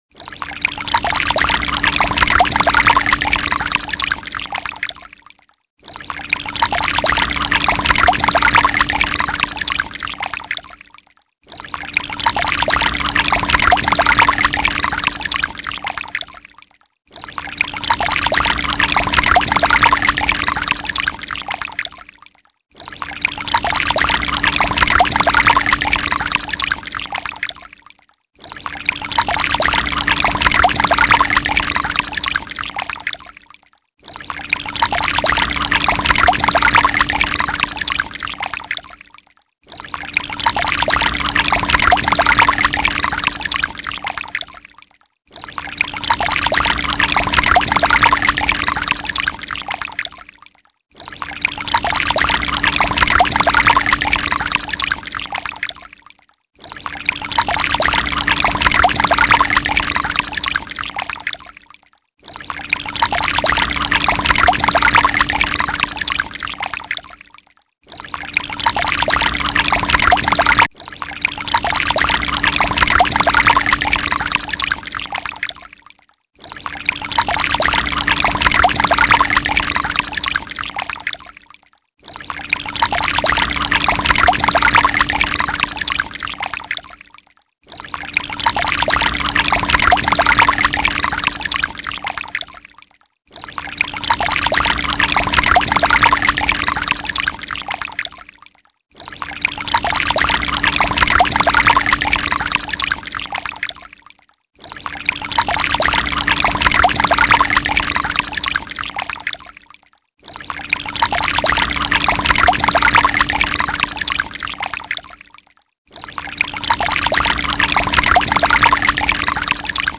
Pot, Bébé et toilettes, Aide aux parents, bruits d'eau (256 kbps) 38569
• Catégorie: Bruits d'eau pour faire pipi 1195